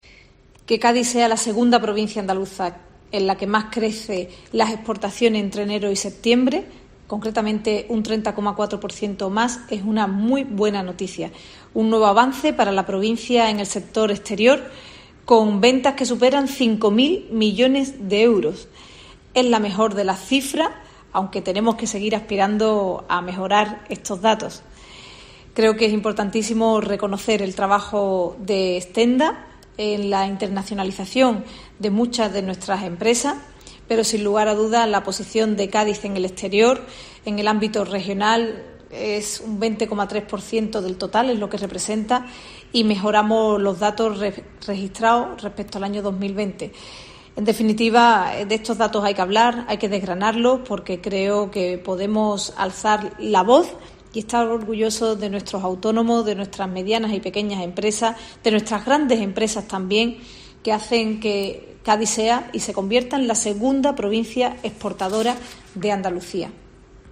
Declaraciones de la Delegada del Gobierno de la Junta en Cádiz, Ana Mestre, sobre exportaciones